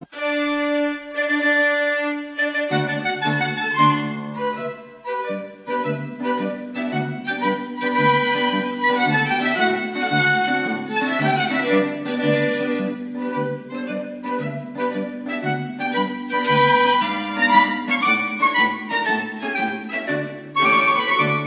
Aufgenommen am 1. - 2. Mai 2000 in Wien